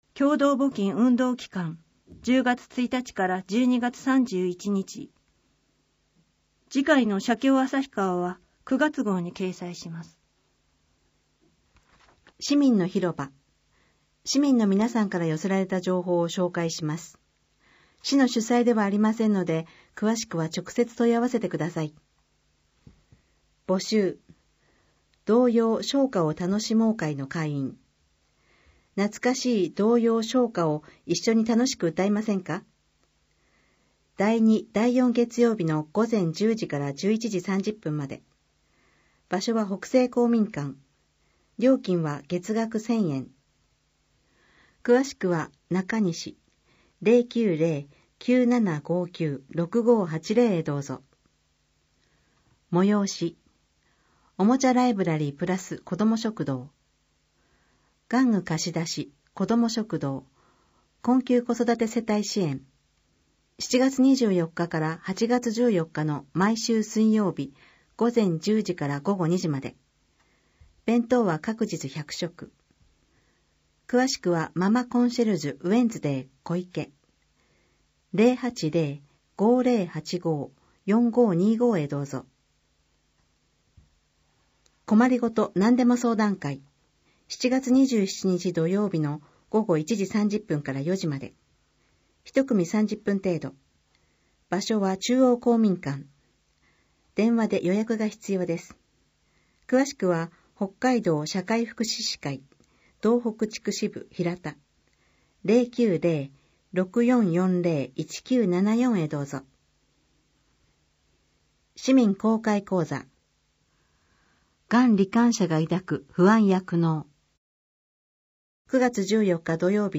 広報誌の内容を要約し、音訳した声の広報「あさひばし」を、デイジー図書版で毎月発行しています。